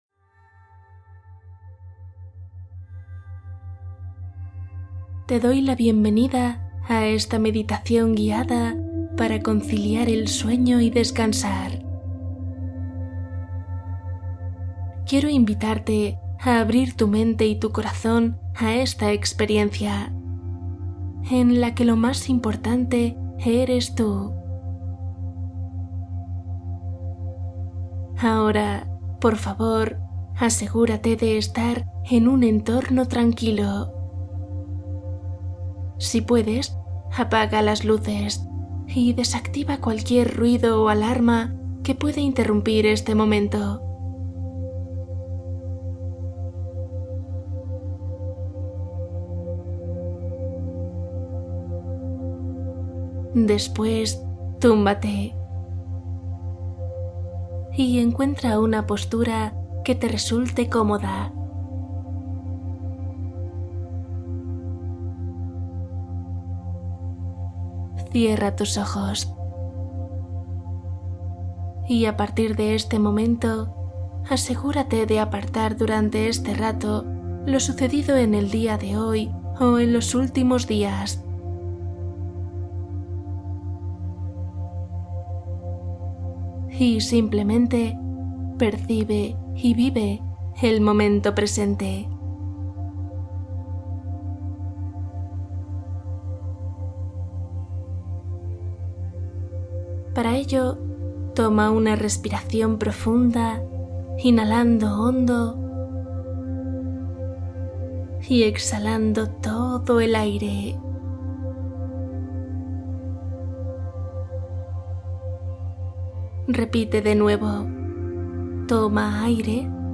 Cuento + meditación | Calma ansiedad y detén los pensamientos